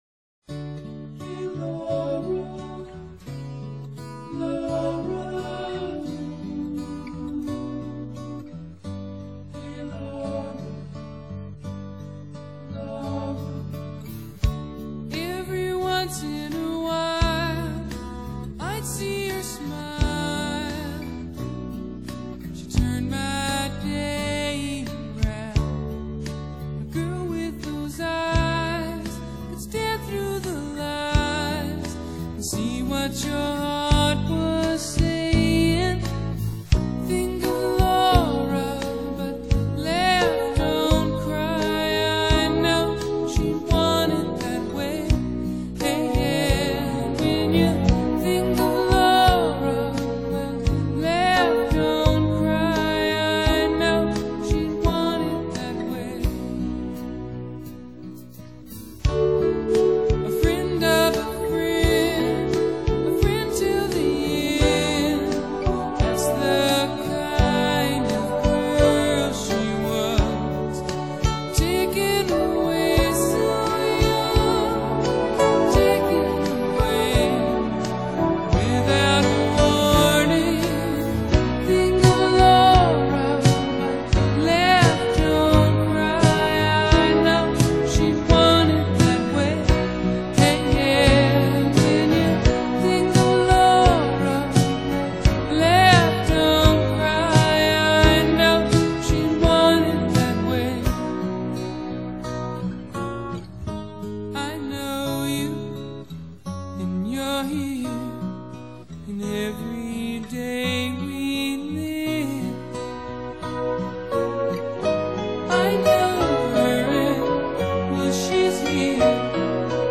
Genre: Pop, Rock | 19 Track | Mp3 | 320 Kbps | 106.78 MB